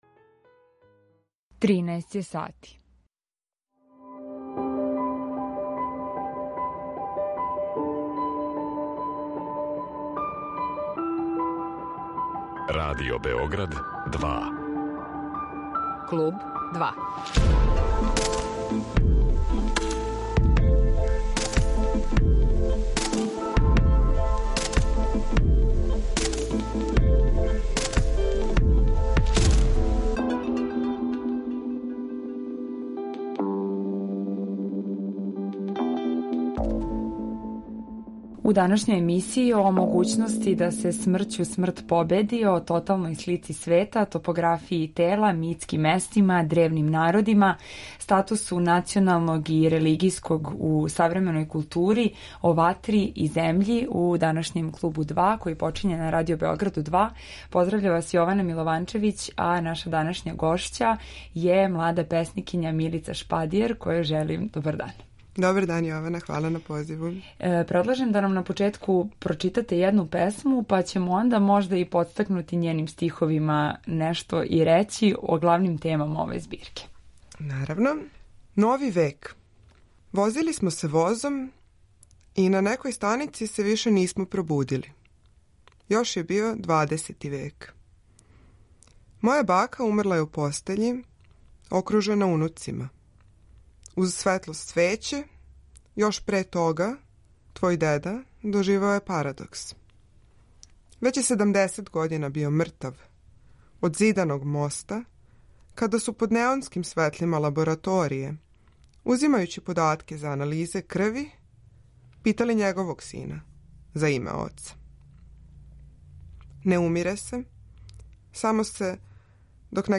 Разговор води